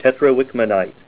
Say TETRAWICKMANITE